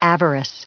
96_avarice.ogg